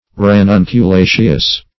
ranunculaceous.mp3